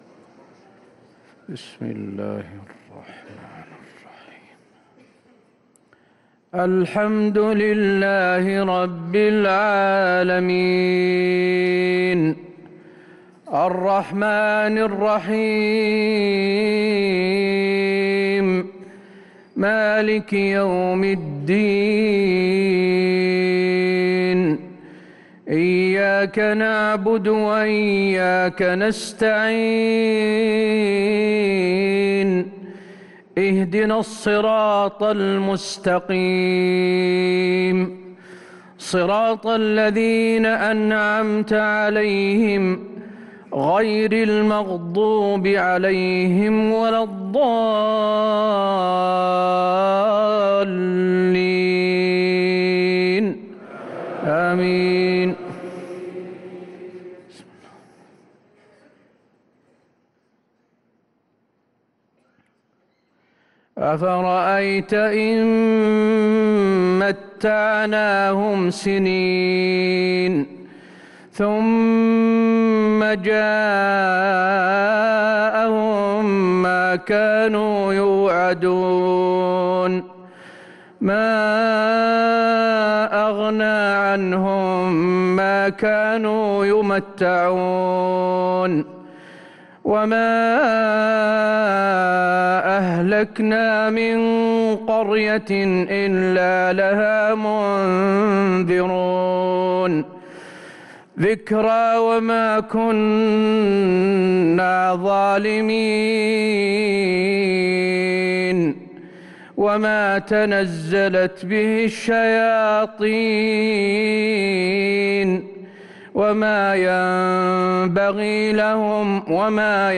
صلاة العشاء للقارئ حسين آل الشيخ 22 رمضان 1444 هـ